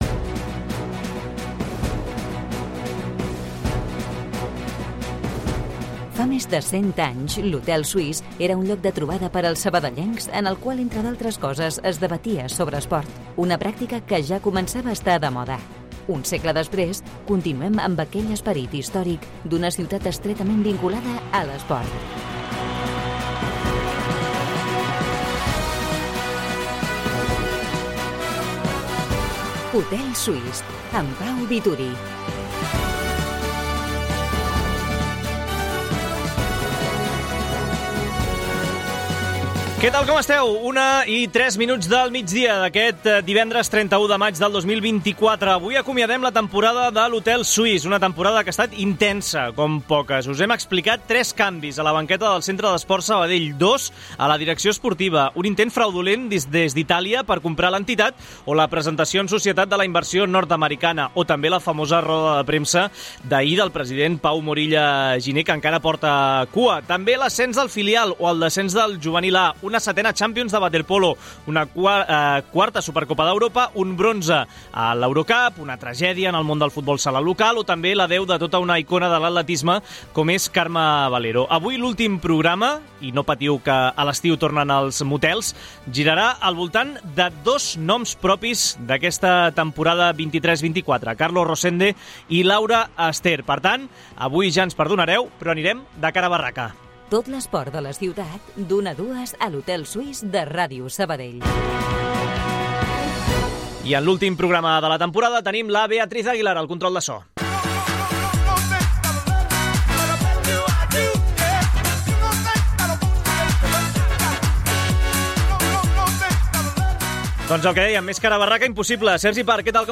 Entrevista de comiat a Laura Ester a Ràdio Sabadell